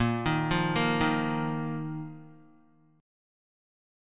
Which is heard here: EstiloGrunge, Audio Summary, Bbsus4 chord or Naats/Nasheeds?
Bbsus4 chord